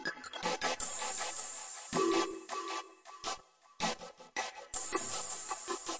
节拍和循环 " 声码器中的鼓声
描述：由声码器实现的简单鼓环。 80 bpm节奏。
Tag: 环境 drumloop 毛刺 效果 节拍 节奏 影响